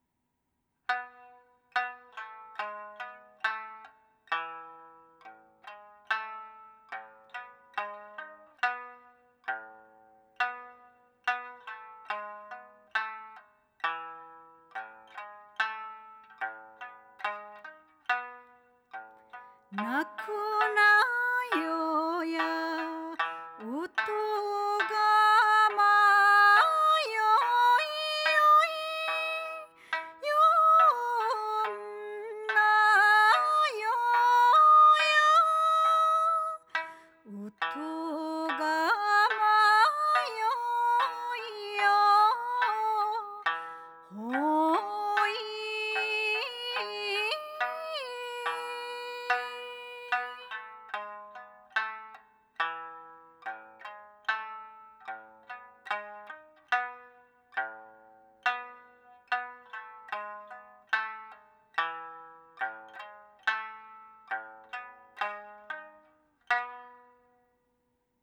ばんがむり（三線）.wav